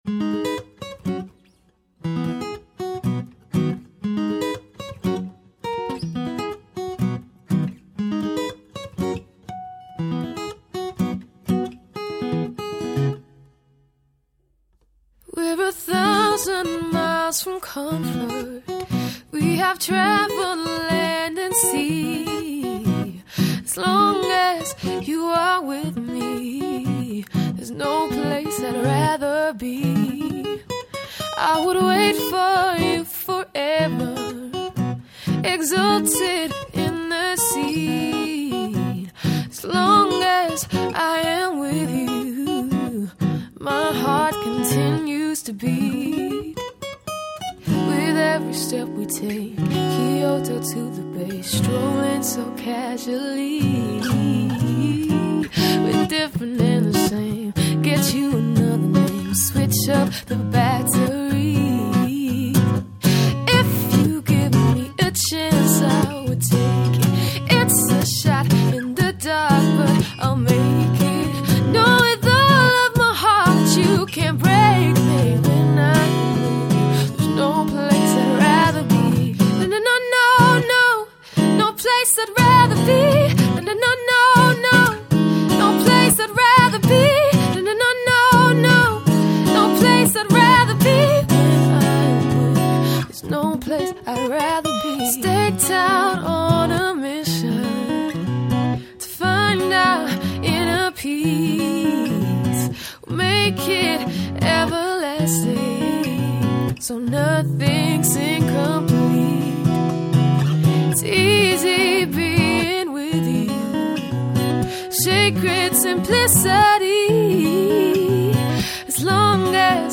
Acoustic Duo for Weddings and Functions
• A Live Lounge twist to classic and contemporary songs